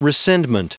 Prononciation du mot rescindment en anglais (fichier audio)
Prononciation du mot : rescindment